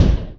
EXPLOSION_Subtle_Dark_stereo.wav